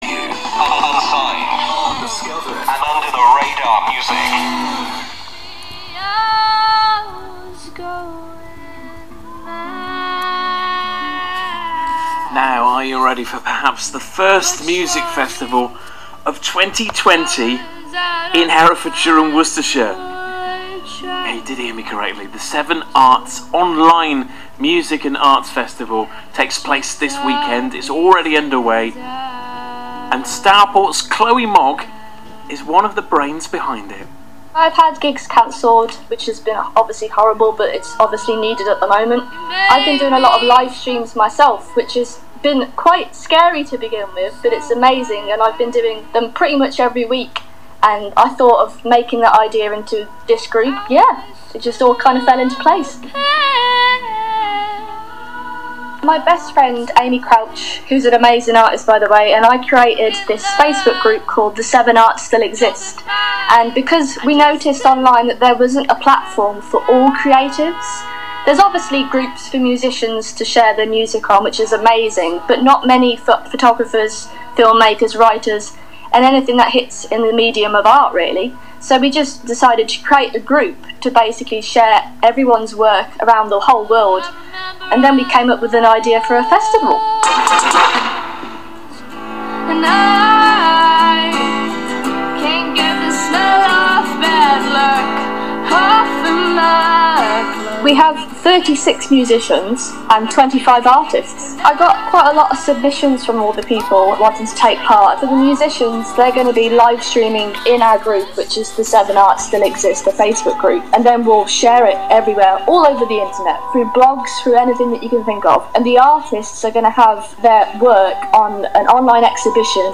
2. Radio stations:
Sadly we were unable to find the first interview which took place around the 1st of April (not an April’s Fool joke, we assure you!), but we had secured the second one from 15th of April.